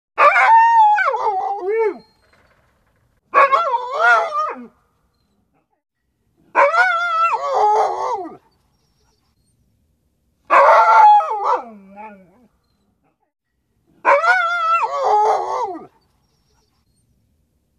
Dog_Unhappy-01.wav